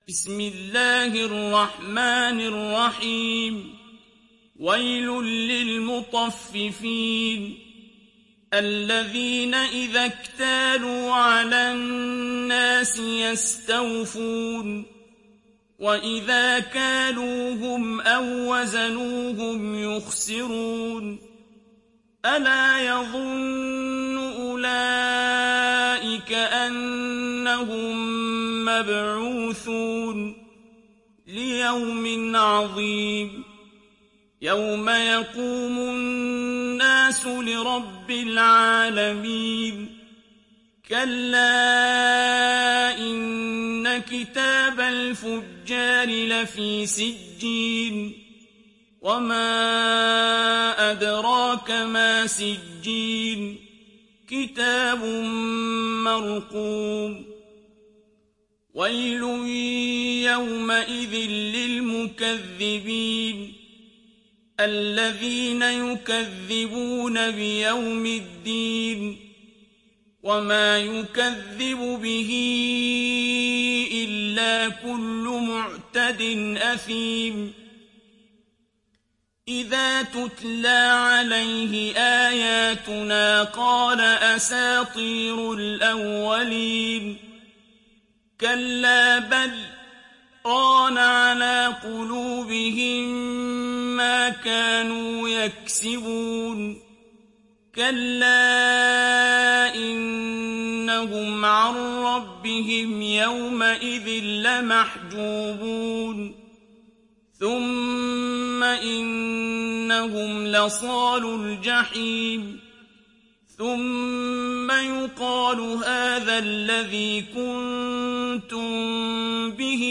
Surah Al Mutaffifin Download mp3 Abdul Basit Abd Alsamad Riwayat Hafs from Asim, Download Quran and listen mp3 full direct links